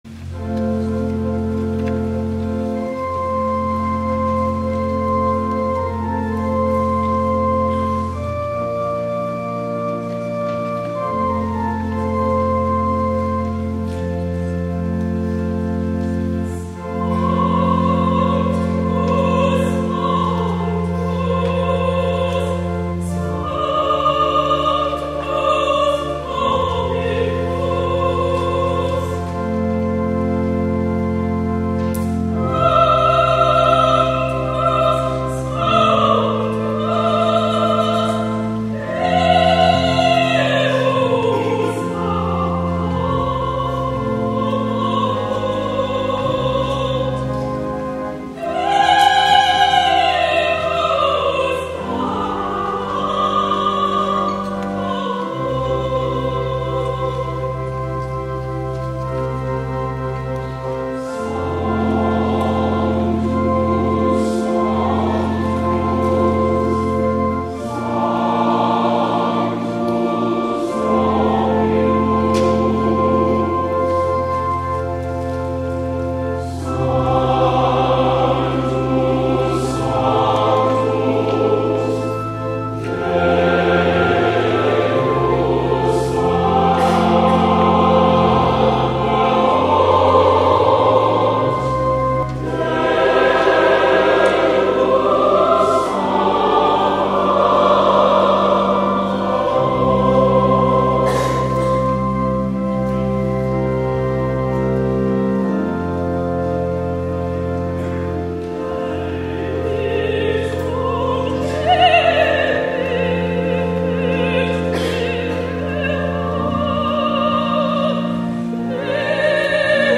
A Service of Worship
THE ANTHEM
soprano